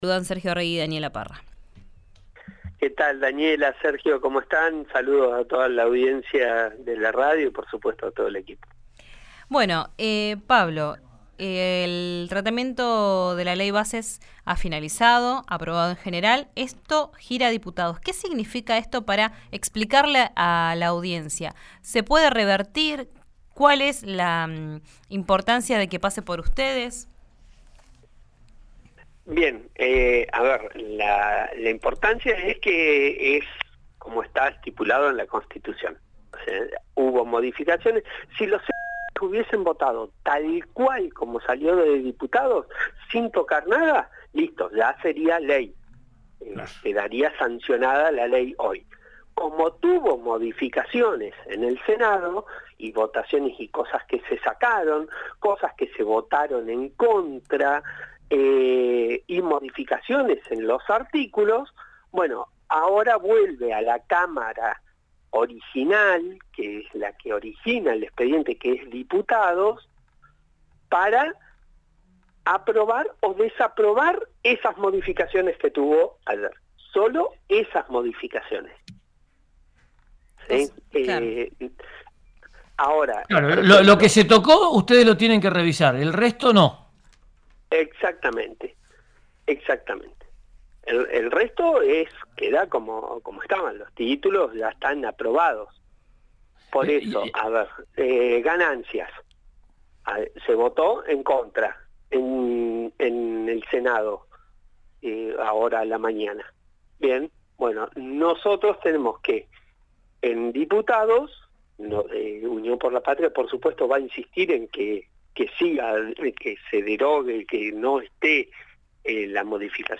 Escuchá a Pablo Todero, diputado nacional por Unión por la Patria, en RÍO NEGRO RADIO: